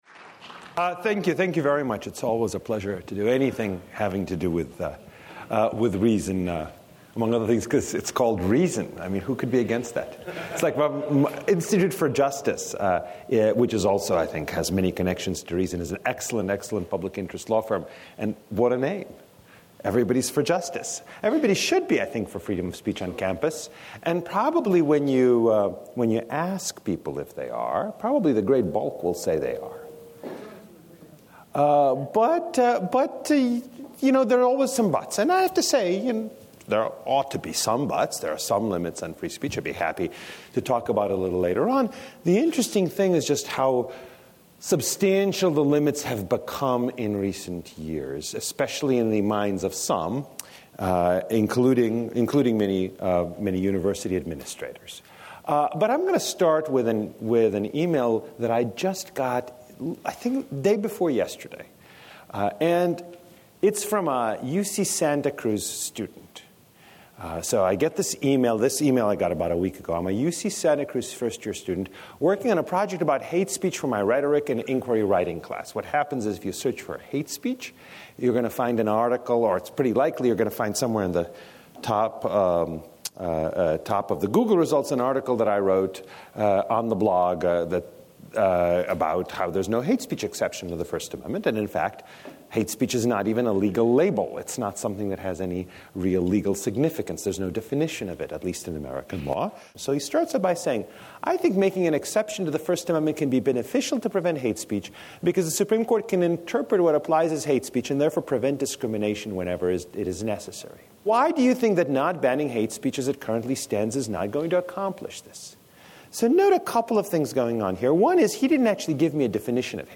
Volokh spoke at Reason Weekend , the annual event held by Reason Foundation.